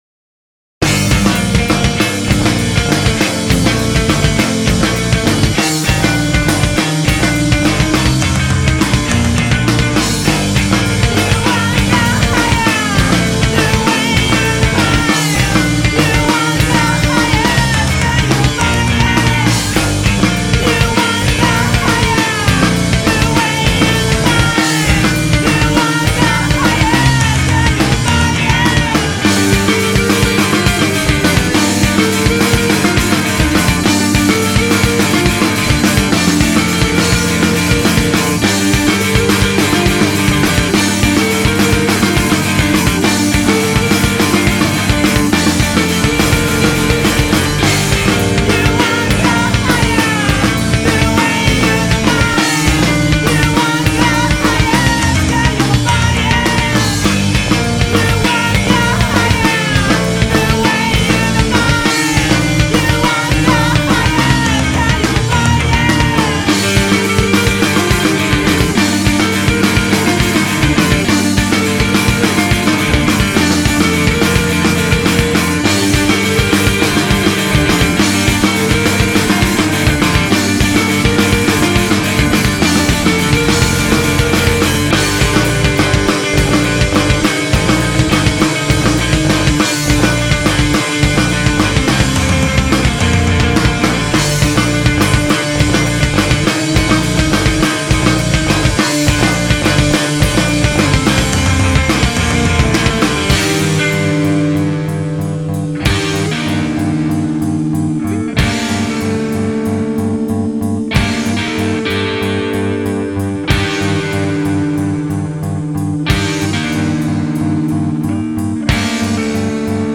recorded and mixed in autumn 05
dedito a un post-punk emotivo e teso.